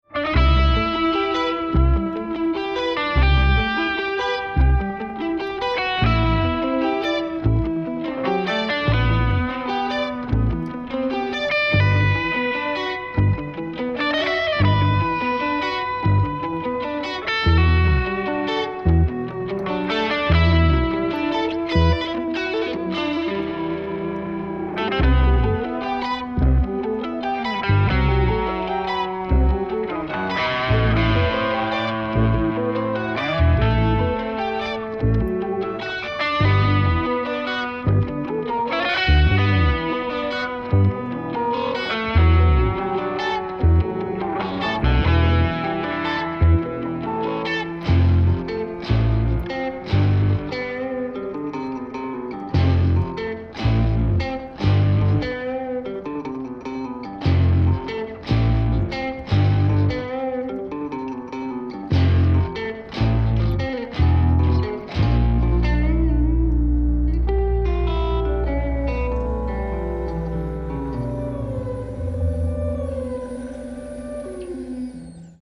There is news: The first sounds of the JAFmaster guitar are here – and the neck is finished!